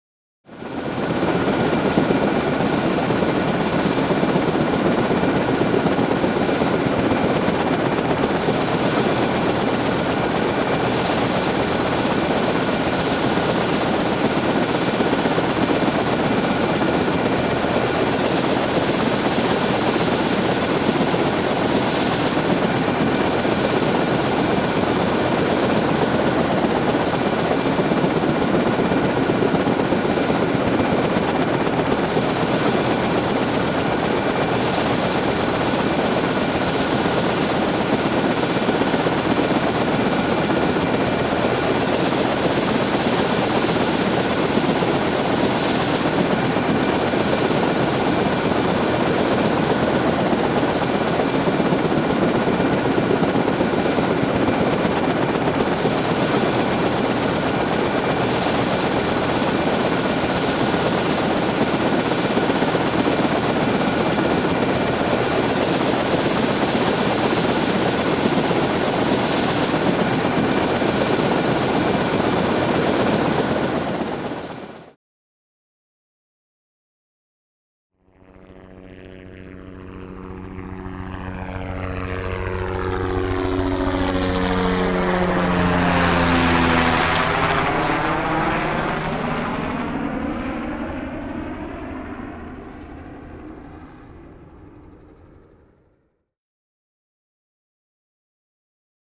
transport
Helicopter Huey Idle Blade Chopping